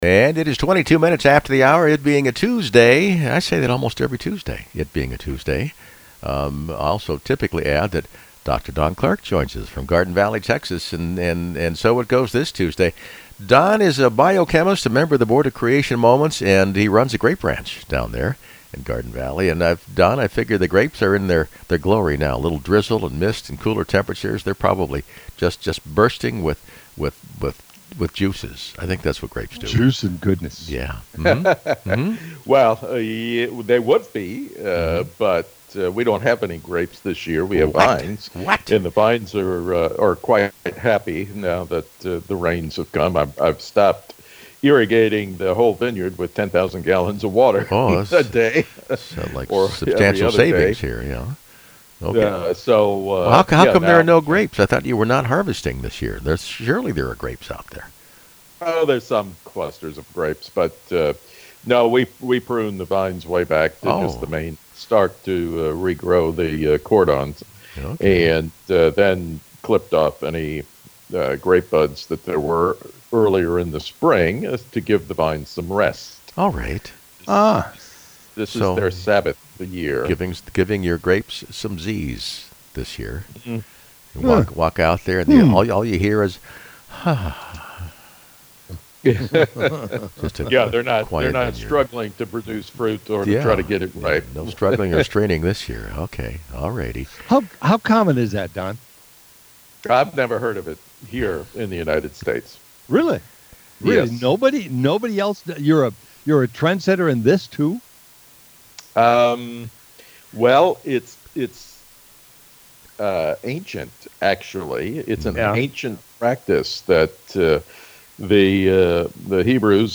MORNING SHOW, JANUARY 19, 2017: Medi-Share …the “non-insurance” ministry